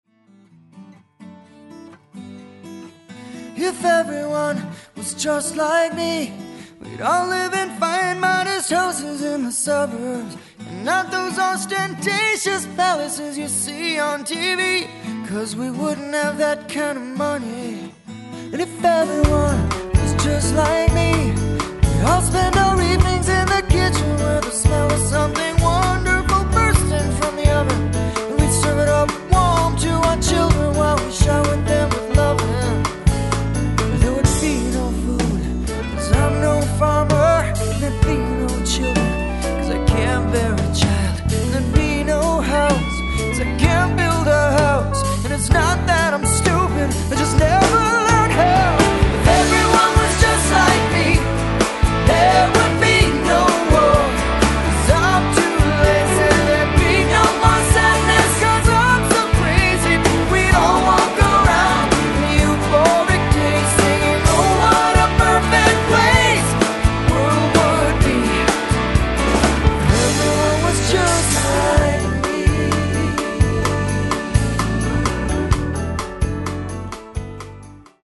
vocals, guitars, mandolin
drums, percussion, vocals
piano, organ, guitars, mandolin
bass
saxophones
background vocals
the tempermill, ferndale, michigan